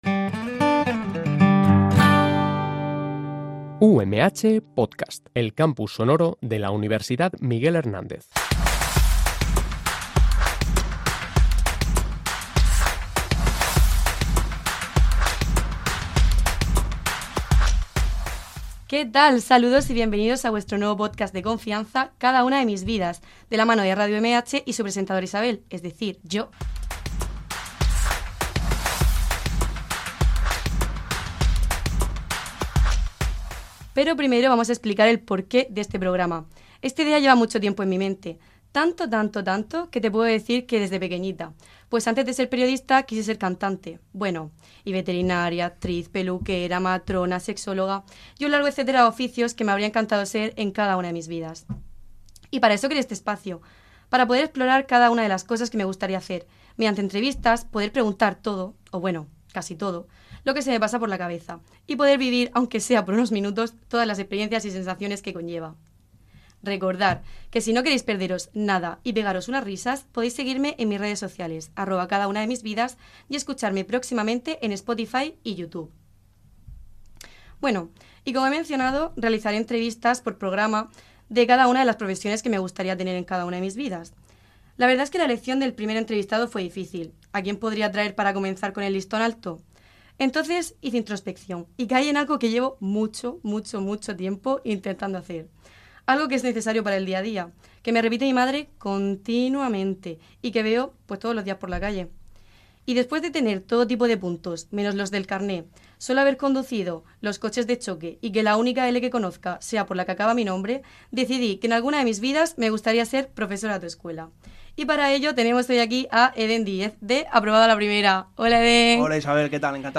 Radio UMH »